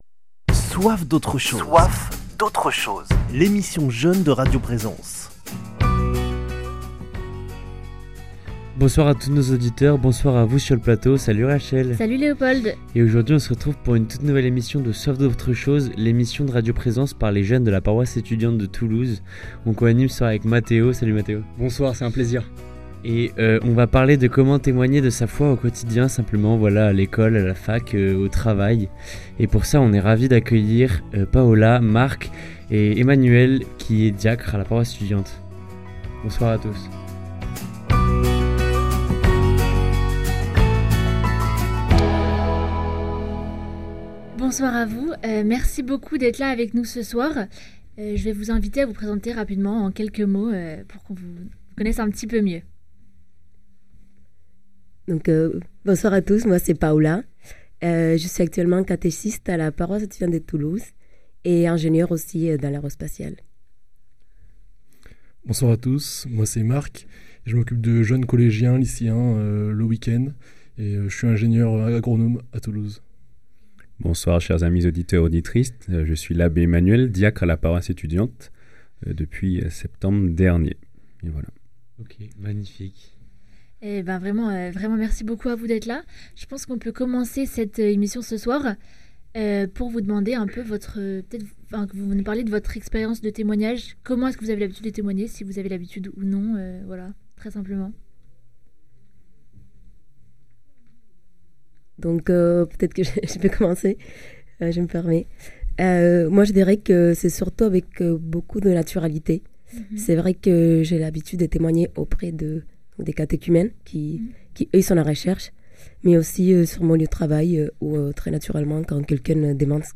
Témoigner de sa foi au cœur de son quotidien se révèle souvent difficile… De la discussion entre amis à l’évangélisation de rue, les invités nous partagent leur témoignage de vie et leurs meilleurs conseils pour vivre pleinement, avec engagement et simplicité, la mission de prophète à laquelle Dieu nous appelle.